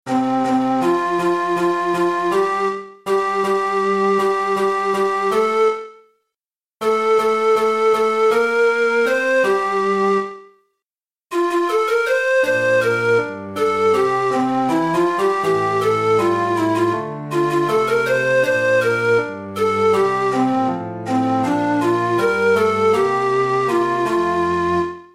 Chorproben MIDI-Files 481 midi files